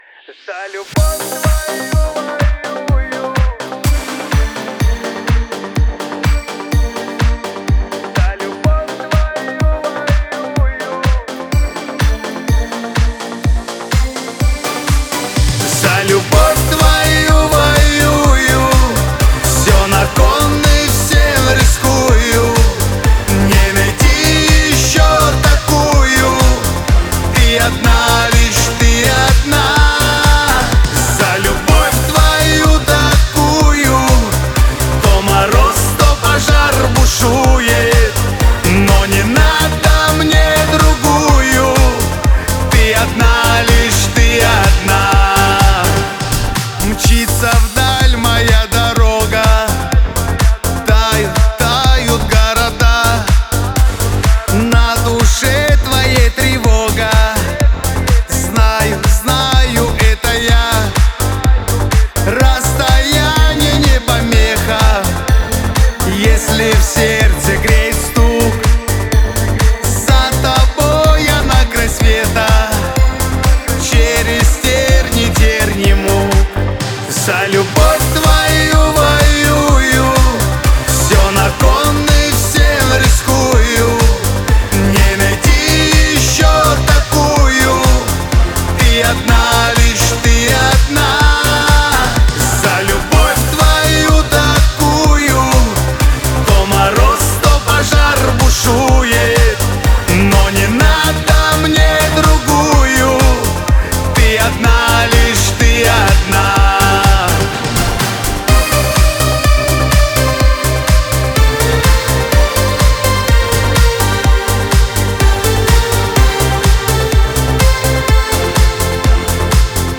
Скачать музыку / Музон / Кавказская музыка 2024